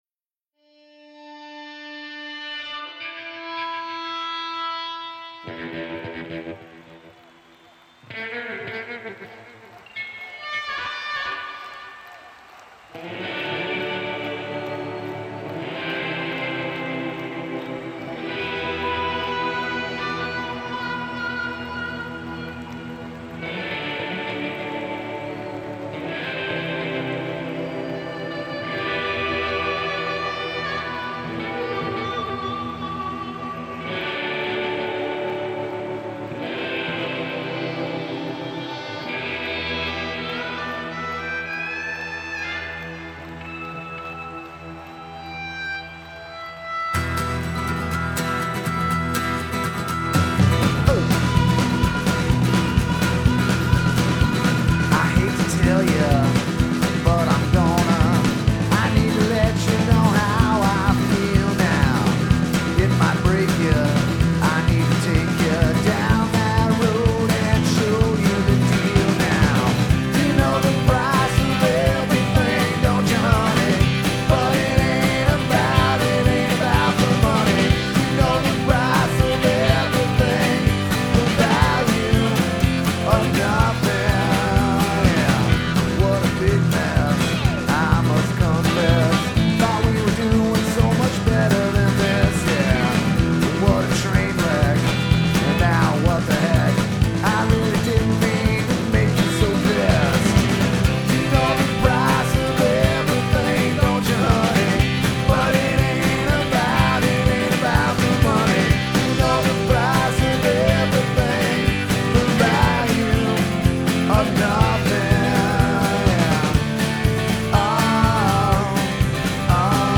ramshackle Americana
With his throwaway stage name and kick-ass growl